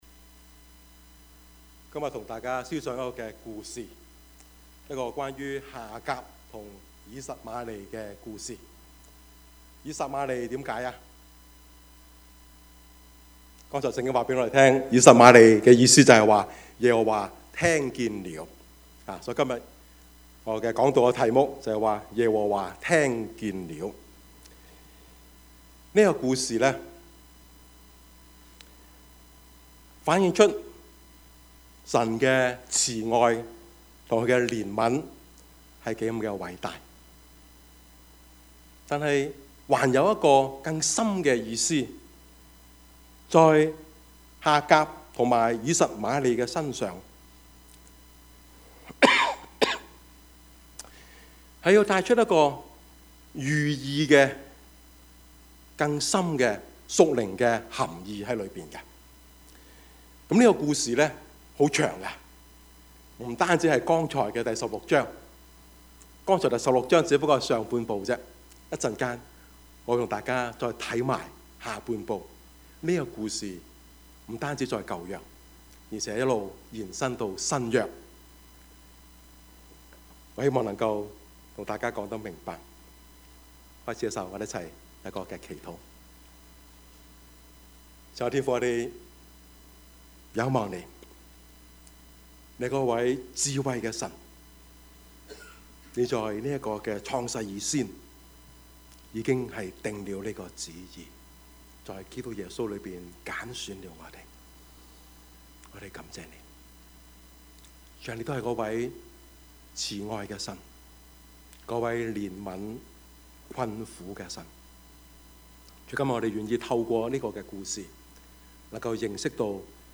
Passage: 創 世 記 16:1-16 Service Type: 主日崇拜
Topics: 主日證道 « 溝通的藝術 當神開路時 »